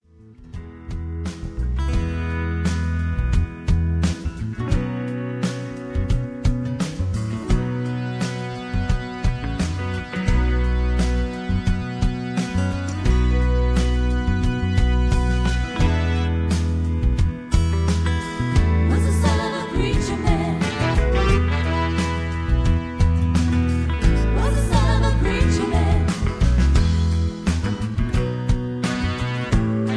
Key-F-D) Karaoke MP3 Backing Tracks
Just Plain & Simply "GREAT MUSIC" (No Lyrics).
mp3 backing tracks